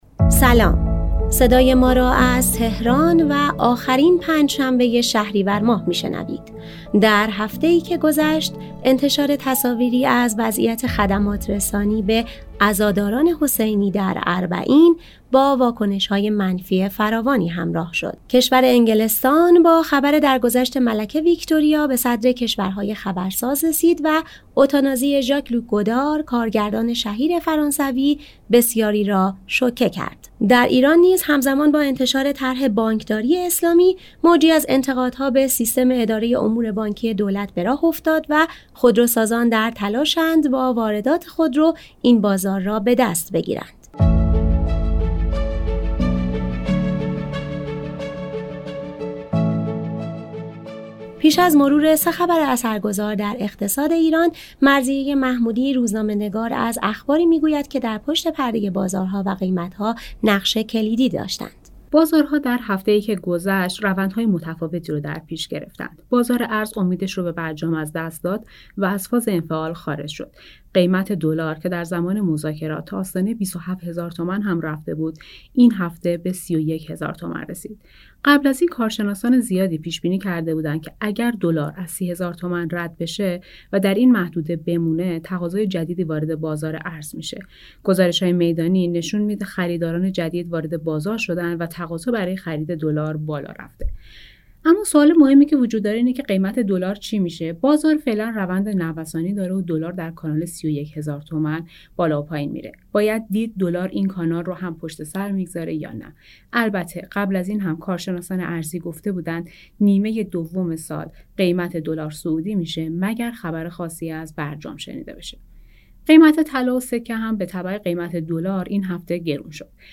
در دوازدهمین پادکست رادیو تجارت نیوز از زبان کارشناسان بازارهای مالی، وضعیت هفته آینده را پیش بینی می‌کنیم و سعی داریم به این سوال جواب بدهیم که سرمایه‌های اندک خود را در چه بازارهای می‌توانید سرمایه گذاری کنید: